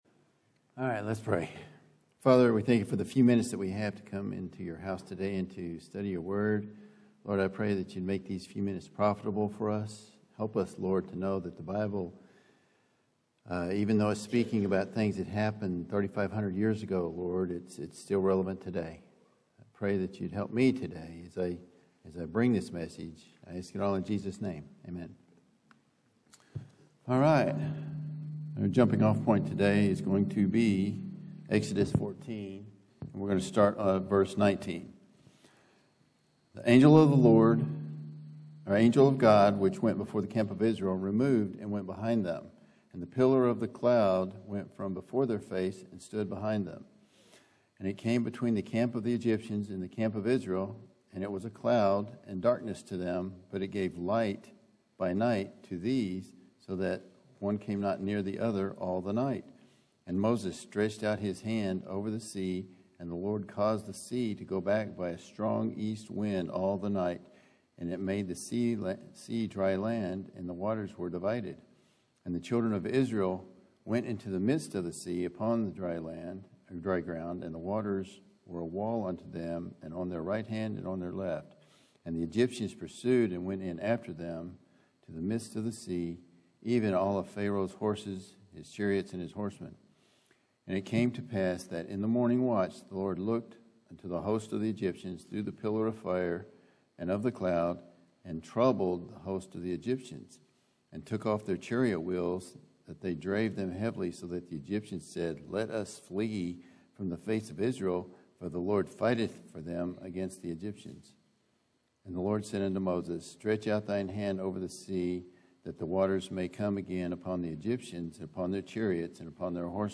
Lesson 1: Moses in the Palace – Learning to Honor God (Exodus 1:8-2:15)From 3/30/25 Sunday School